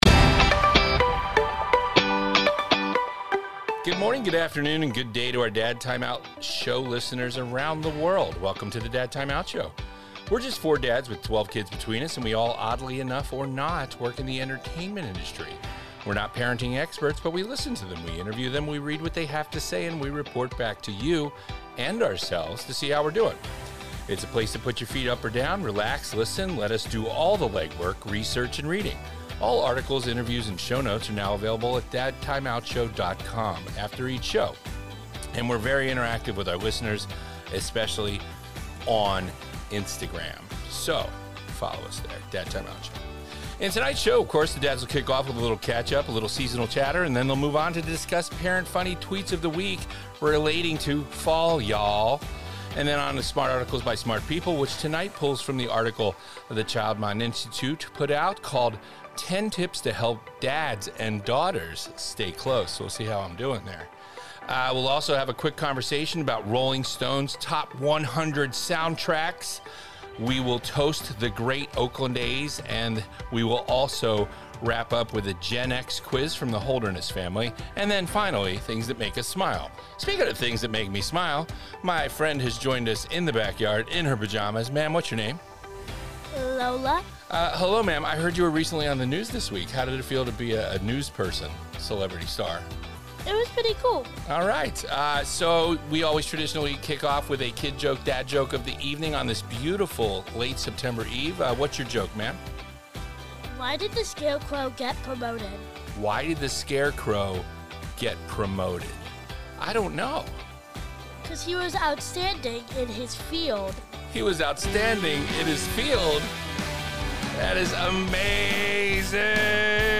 A bi-weekly conversation between four dads from the entertainment industry with twelve kids of all ages learning from parenting experts and sharing with you. Fatherhood, family, growth, optimism and hope.